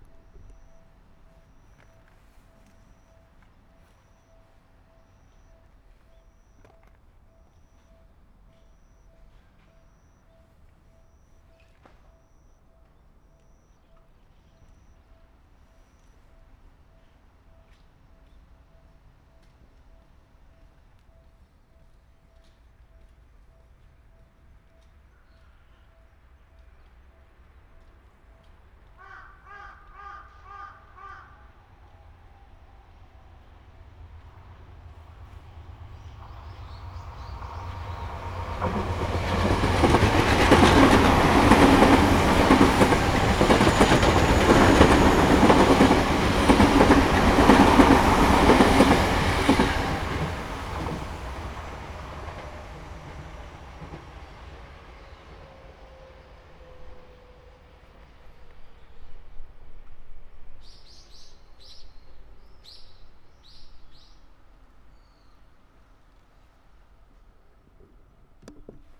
M2とM4のXYマイク指向性90°と同じにしました
下り電車通過。
M2内蔵マイク＋へアリーウインドスクリーンWSU-1